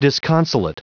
Prononciation du mot disconsolate en anglais (fichier audio)
Prononciation du mot : disconsolate